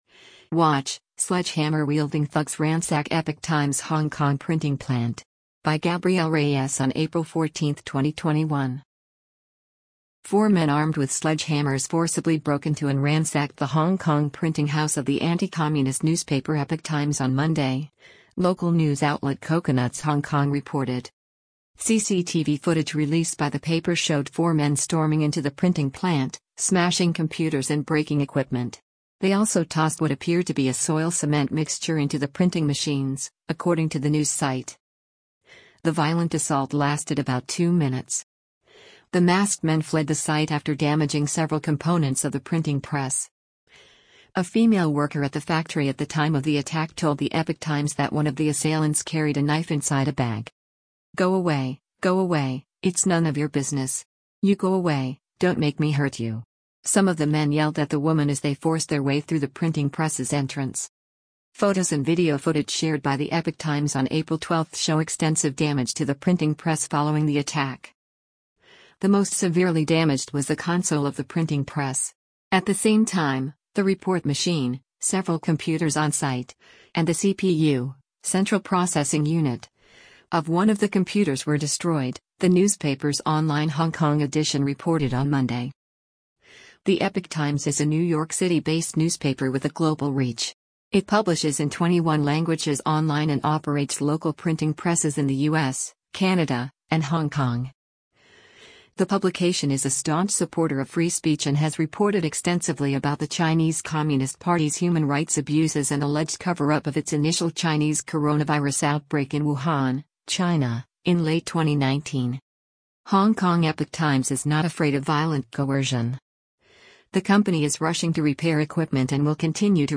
“CCTV footage released by the paper showed four men storming into the printing plant, smashing computers and breaking equipment. They also tossed what appeared to be a soil-cement mixture into the printing machines,” according to the news site.